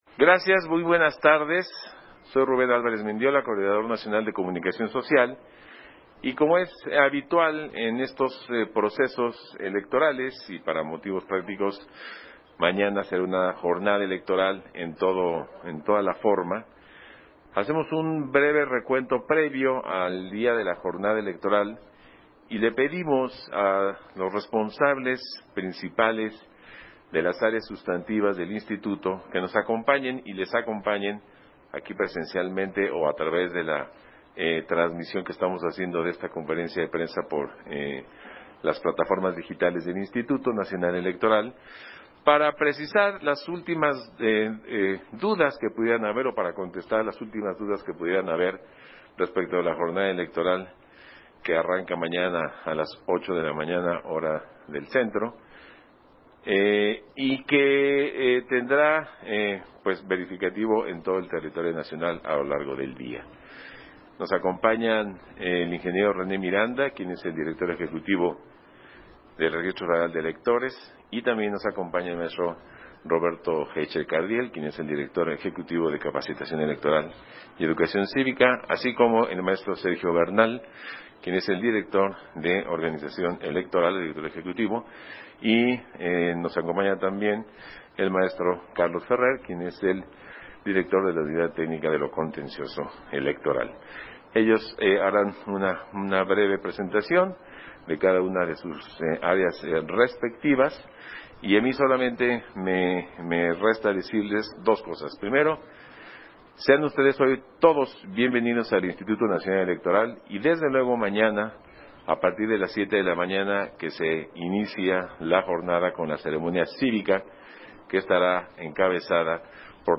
090422_AUDIO_CONFERENCIA-DE-PRENSA-RM